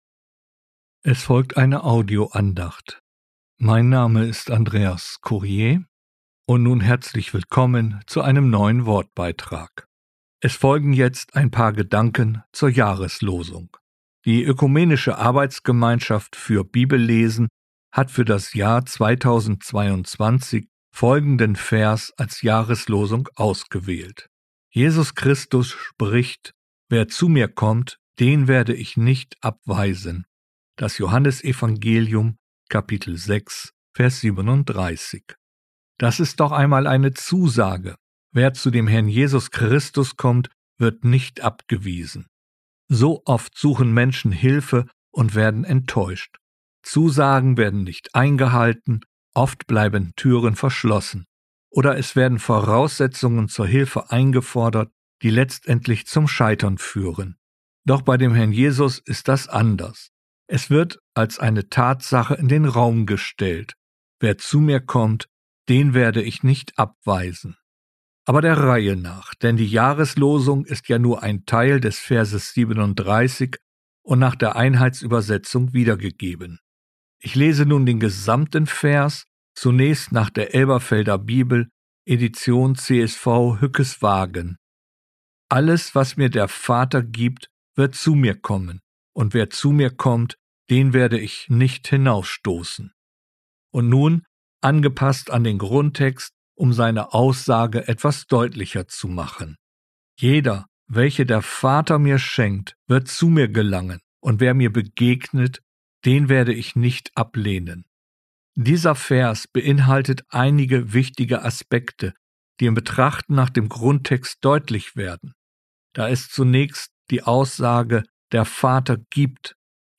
Eine Kurzpredigt über die Jahreslosung 2022. Auch eine Zusage und Einladung zu Jesus zu kommen.